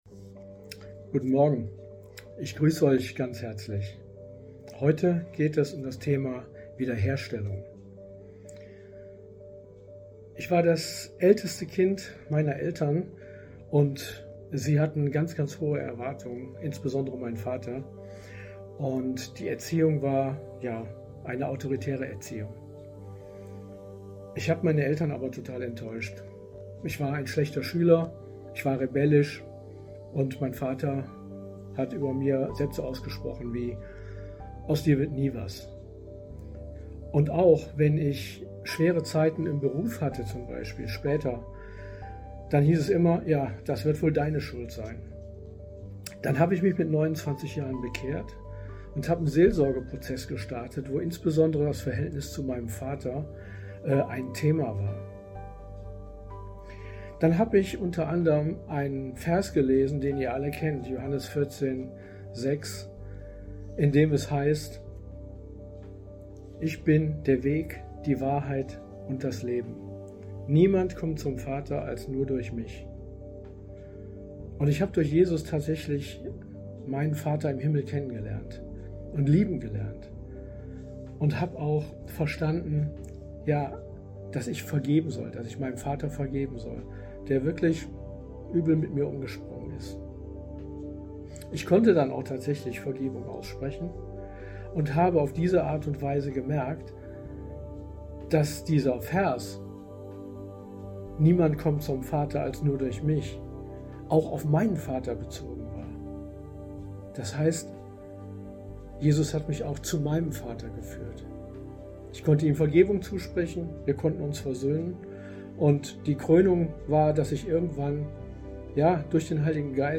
Tag 3 der Andacht zu unseren 21 Tagen Fasten & Gebet